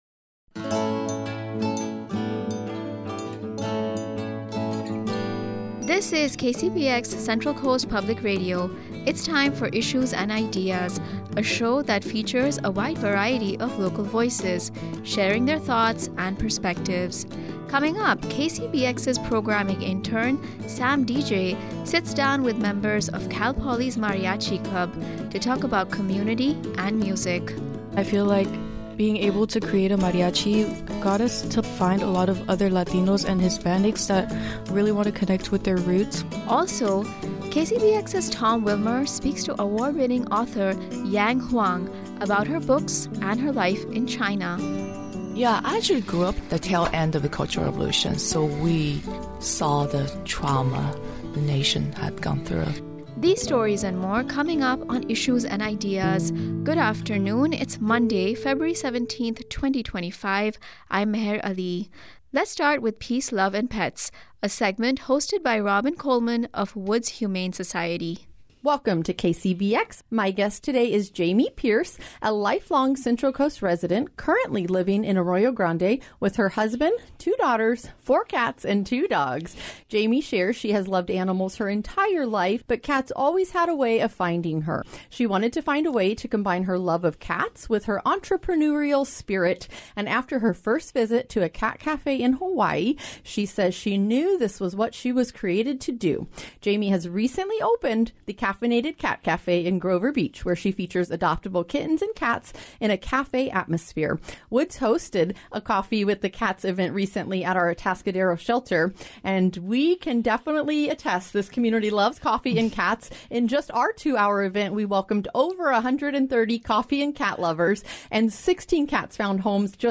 Issues and Ideas is a show that features a wide variety of people sharing their thoughts and perspectives about local issues, initiatives and events on the Central Coast.